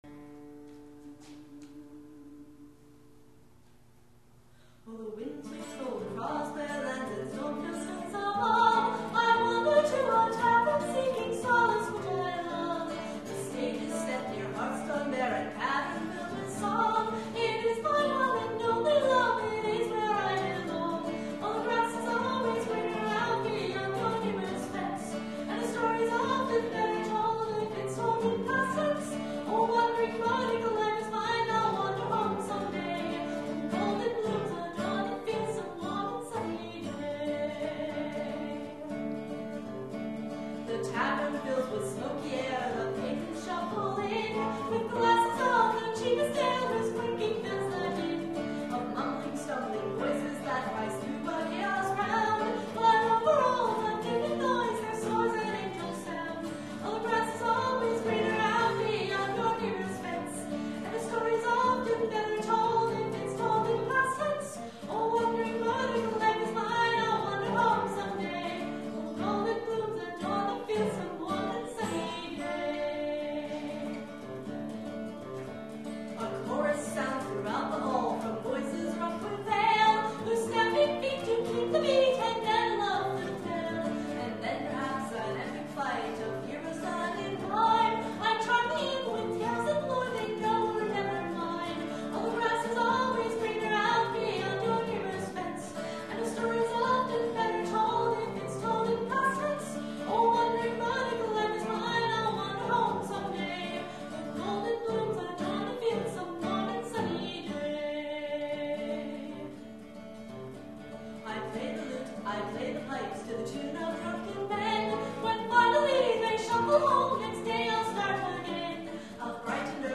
This is a romanticized imagining of what a traveling bard’s life is like, minus the hunger and cold and weariness.  It’s meant to be a show piece, which is why it extends further up and down the scale than most of my songs do.
The copy on the website is from my minor project, and is a live performance.  Apologies for the poor sound quality.